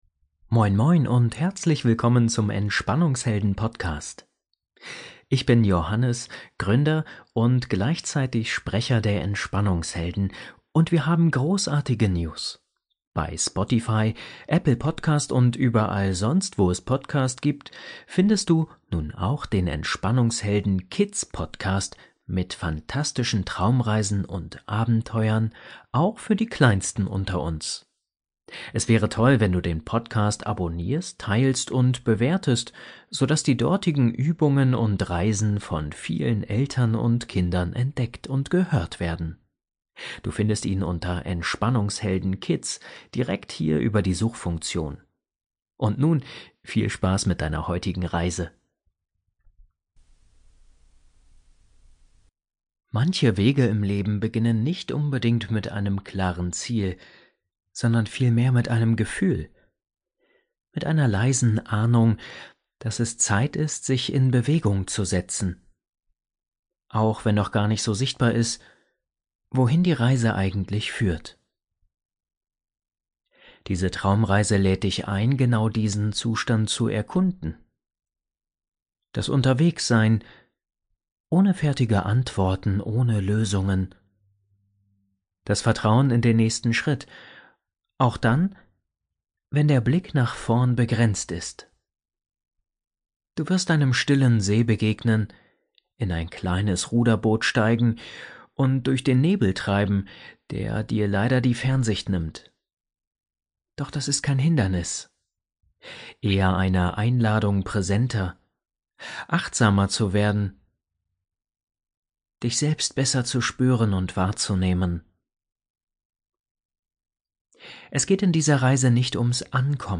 Traumreise: Überfahrt über den Nebelsee ~ Entspannungshelden – Meditationen zum Einschlafen, Traumreisen & Entspannung Podcast